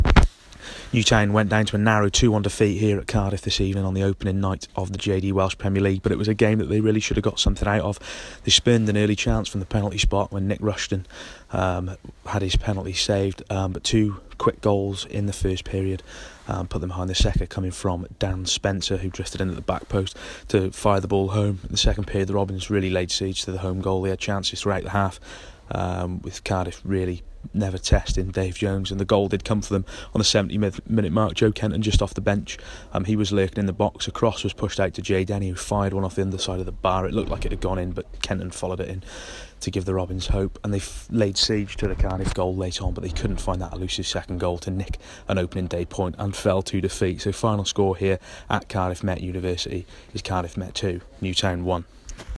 AUDIO REPORT - Cardiff 2-1 Newtown